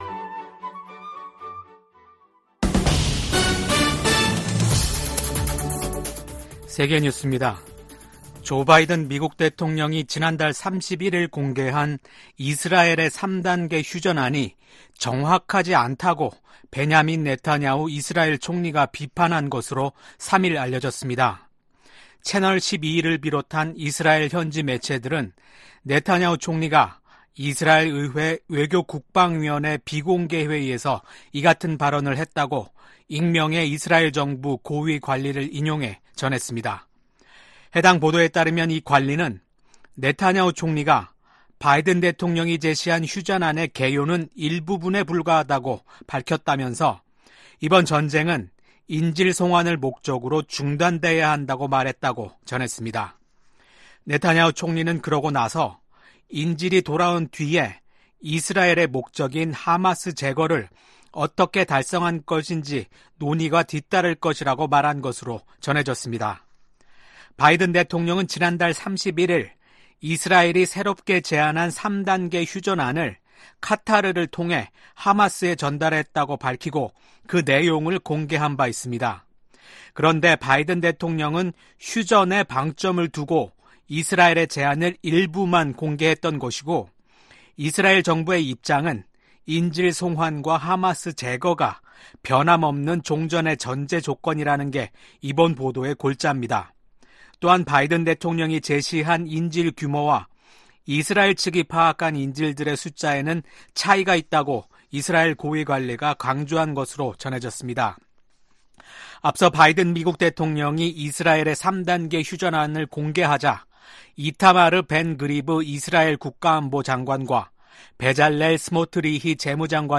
VOA 한국어 아침 뉴스 프로그램 '워싱턴 뉴스 광장' 2024년 6월 4일 방송입니다. 한국 정부는 9.19 군사합의 전체 효력을 정지하는 수순에 들어갔습니다. 북한 ‘오물 풍선’ 에 맞서 대북 확성기 방송 재개를 위한 사전 조치로 보입니다. 미국과 한국, 일본이 국방장관 회담을 열고 올 여름부터 새로운 군사훈련을 실시하기로 합의했습니다.